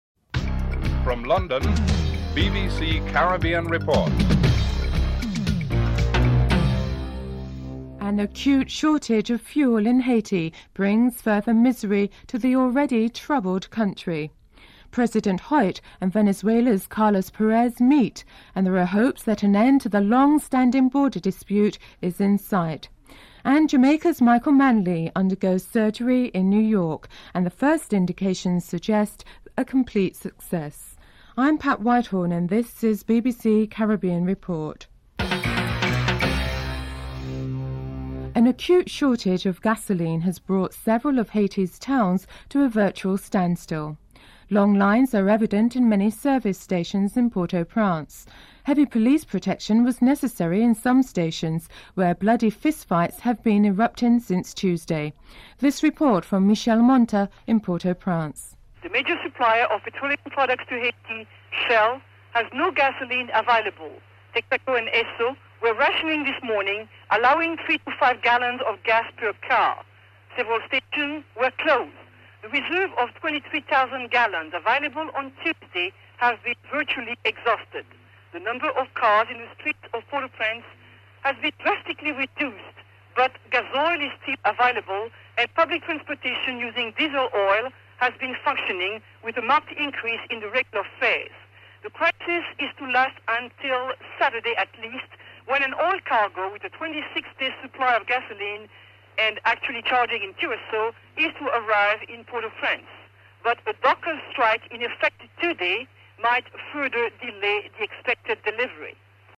1. Headlines (00:00-00:37)
4. Financial News (04:35-05:25)